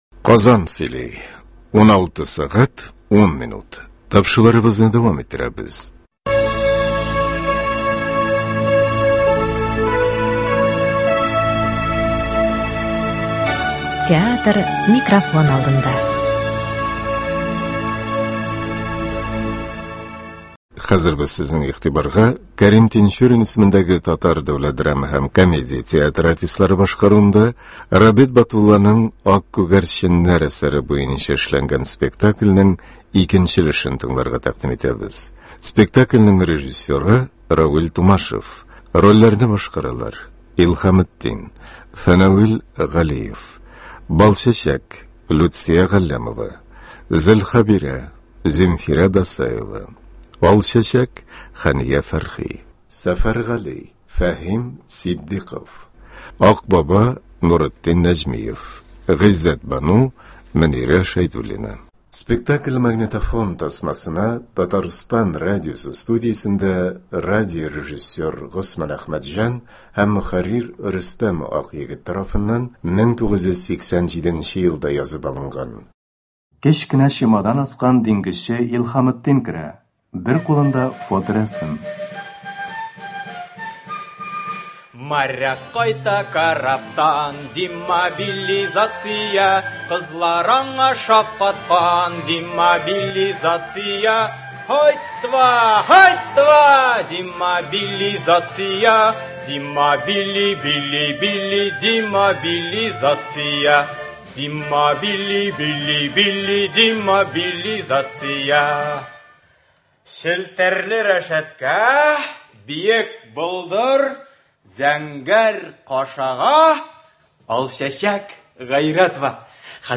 К.Тинчурин ис. ТДДКТ спектакленең радиоварианты.
сезне К.Тинчурин исемендәге Татар Дәүләт драма һәм комедия театрының “Ак күгәрченнәр” водевиль-спектакле язмасын тыңларга чакырабыз.
Биредә бүген инде татар халкының яраткан җырчысы Хәния Фәрхи, җырлы Алчәчәк ролен башкара.